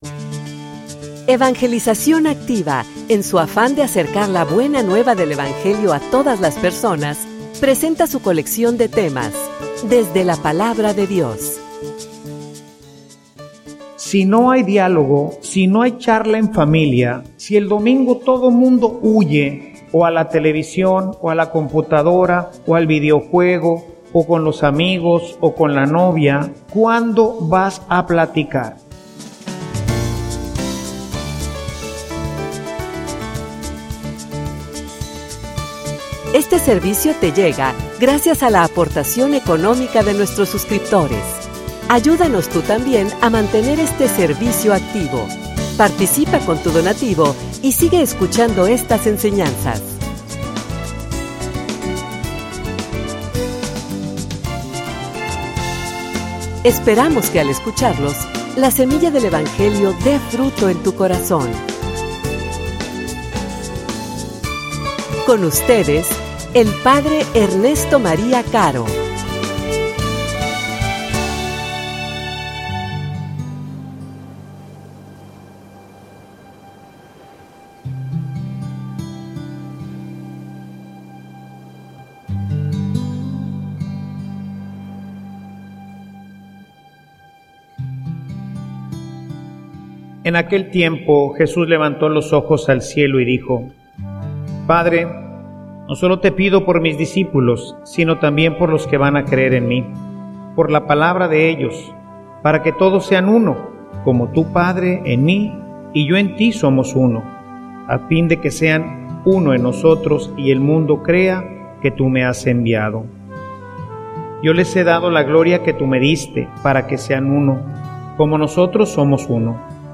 homilia_Tiempo_en_familia.mp3